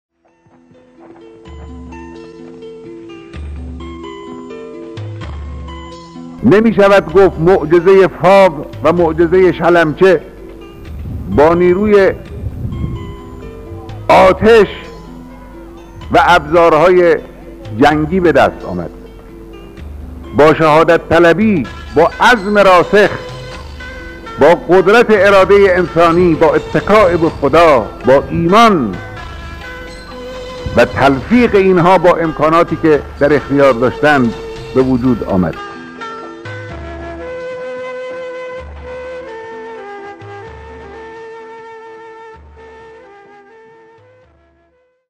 صوت/ بیانات رهبر معظم انقلاب درباره شهدا